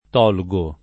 t0rre]) v.; tolgo [
t0lgo], togli — pass. rem. tolsi [t0lSi]; part. pass. tolto [t0lto] — fut. toglierò [tol’l’er0+] (ant. o poet. torrò [torr0+]) — latinismo ant. tollere [t0llere], con -ll- invece di -l’l’‑ davanti a tutte le desinenze che comincino per e o per i; es.: L’Agnel di Dio che le peccata tolle [